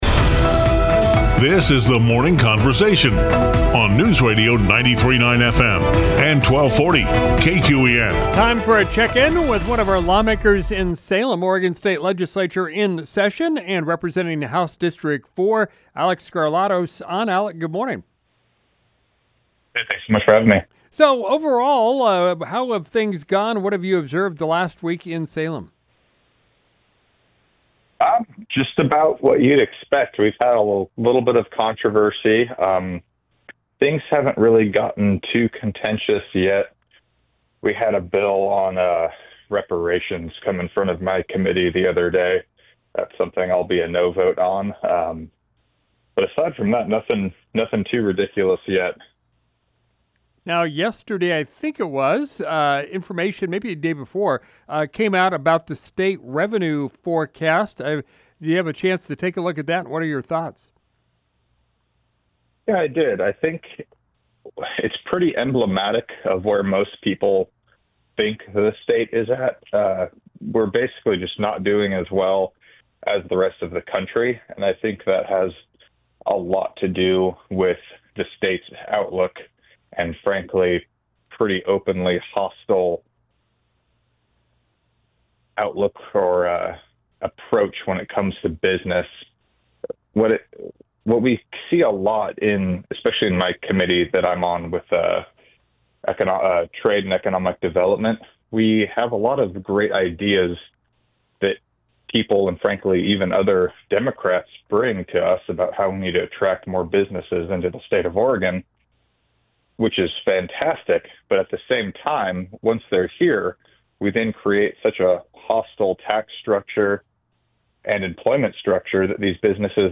State Representative Alek Skarlatos joins us from Salem for an update on legislation under consideration by the Oregon State Legislature.